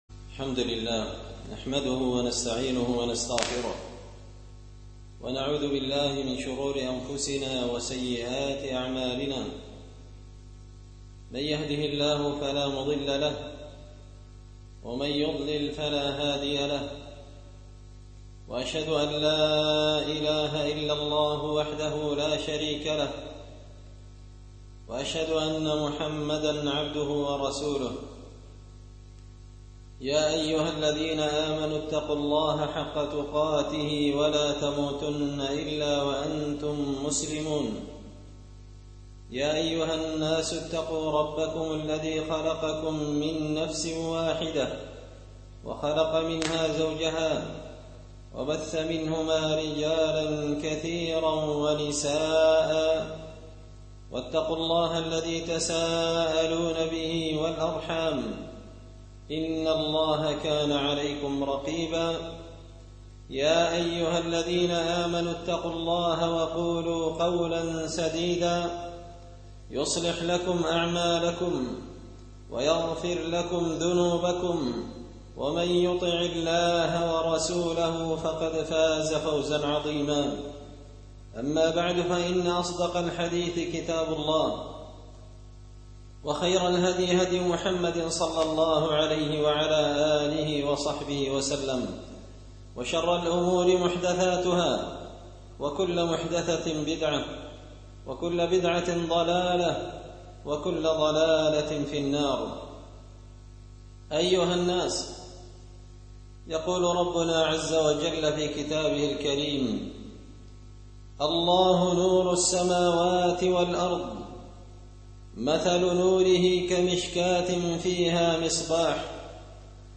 خطبة جمعة بعنوان – الله نور السماوات والأرض
دار الحديث بمسجد الفرقان ـ قشن ـ المهرة ـ اليمن